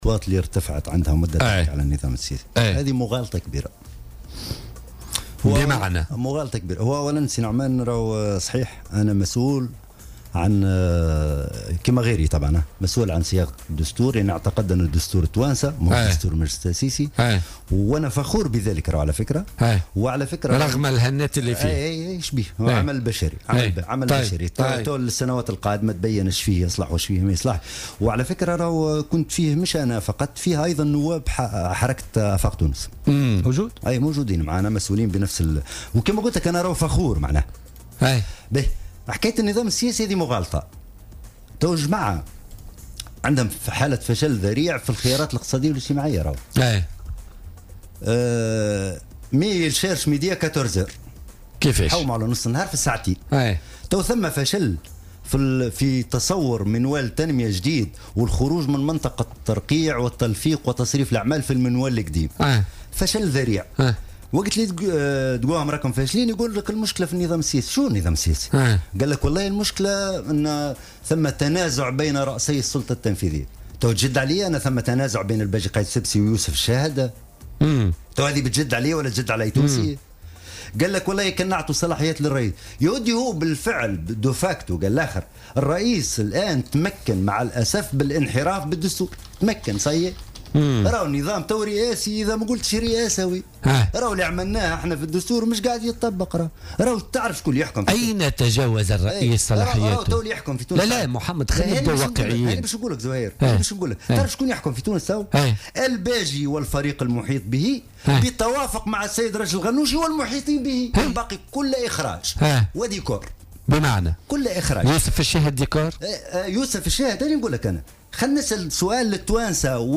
وأشار الحامدي، ضيف "بوليتكا" اليوم الأربعاء أن الباجي قائد السبسي و"الفريق المحيط به" هو من يحكم تونس بالتوافق مع رئيس حركة النهضة راشد الغنوشي، بينما لا يتجاوز دور البقية "الديكور" بمن فيهم رئيس الحكومة يوسف الشاهد، وفق تعبيره.